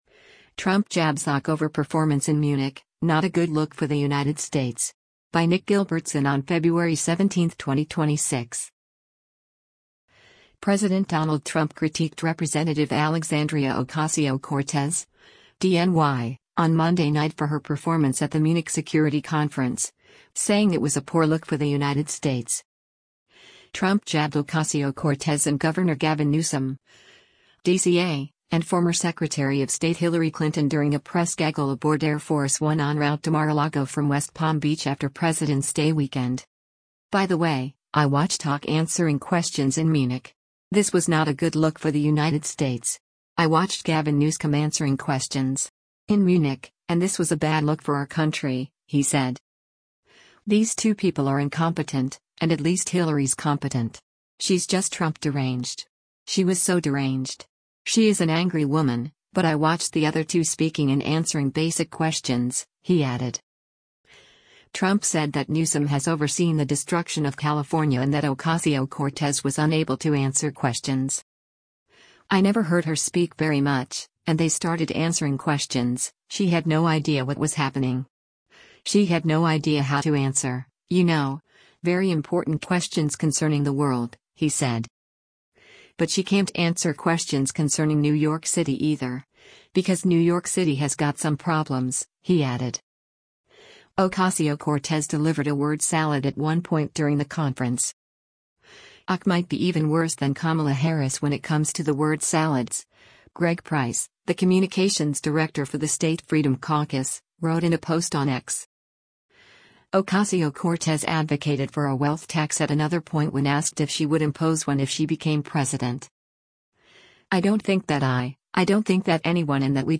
Trump jabbed Ocasio-Cortez and Gov. Gavin Newsom (D-CA) and former Secretary of State Hillary Clinton during a press gaggle aboard Air Force One en route to Mar-a-Lago from West Palm Beach after Presidents’ Day Weekend.